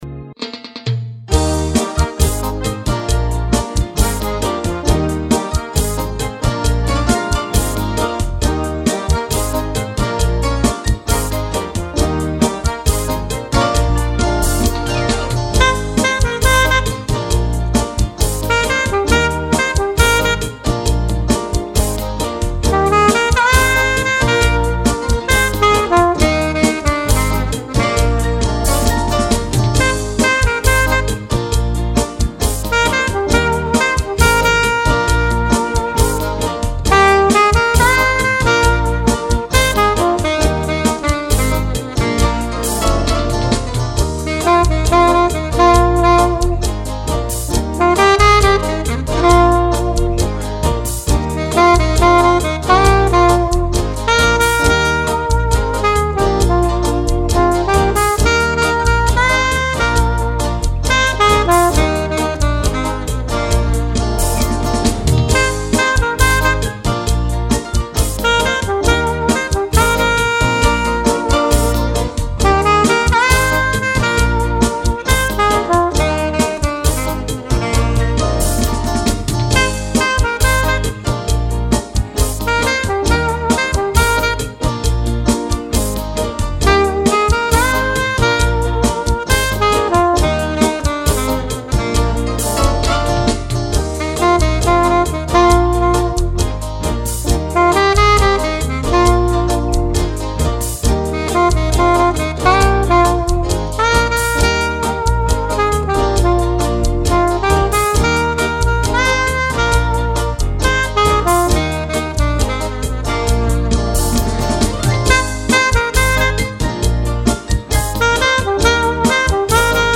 843   05:02:00   Faixa:     Bolero